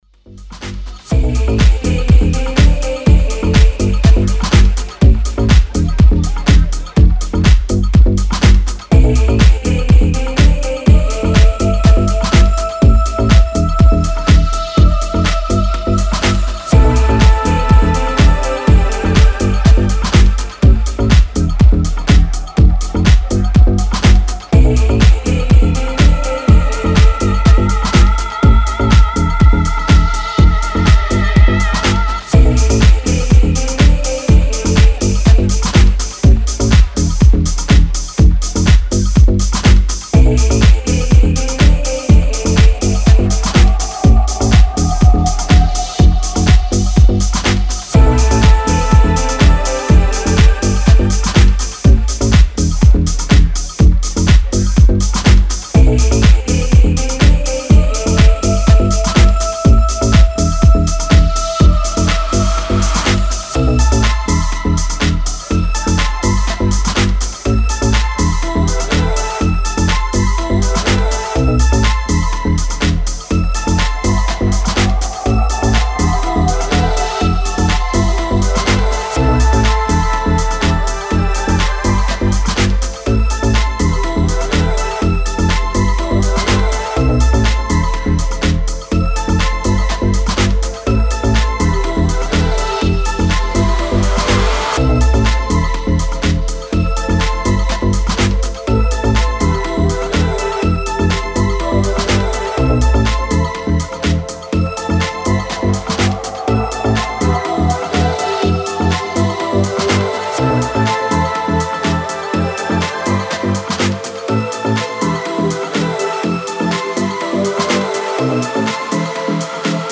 Four classic House trax to make the crowd happy.
Style: House